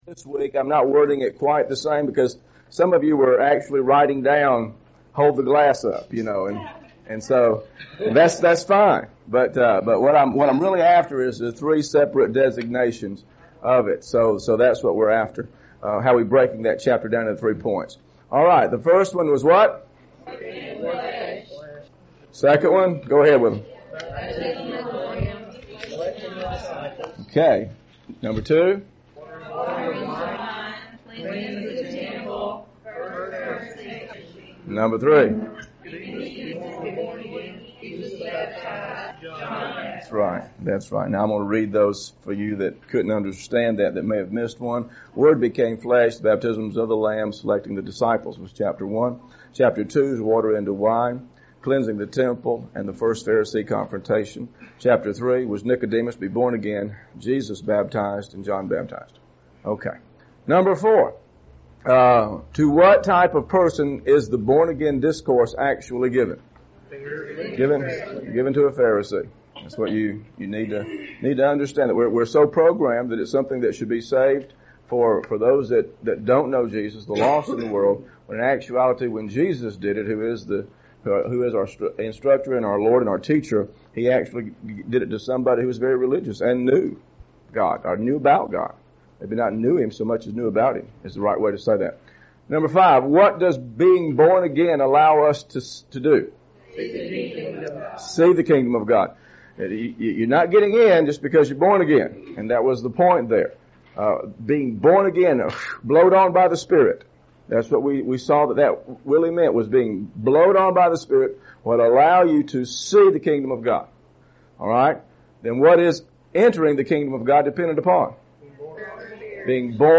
Scriptures used in this lesson: John Chapter 4 John Chapter 4 John Chapter 4 Also see